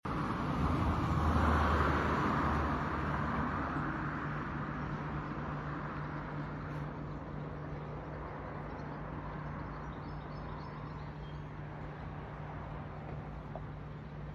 Natural sounds 🇨🇭❤ sound effects free download